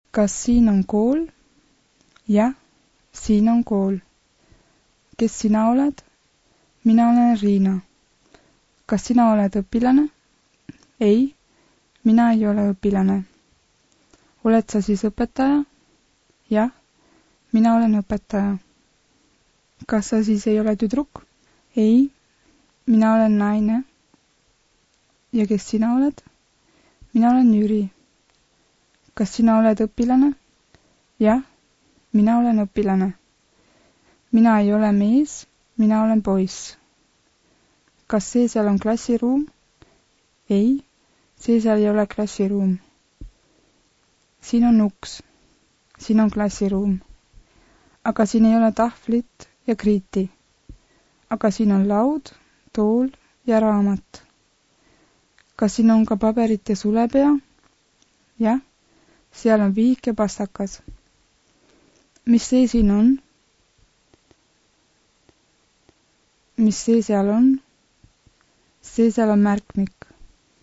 Dies ist das normale Sprechtempo!